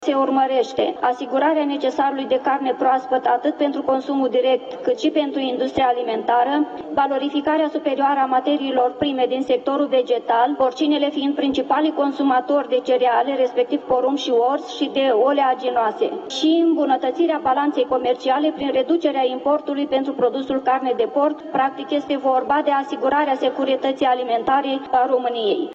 Unul dintre inițiatorii proiectului, senatoarea PSD Doina Silistru a explicat că acest program le va permite românilor să consume mai multă carne de porc românească.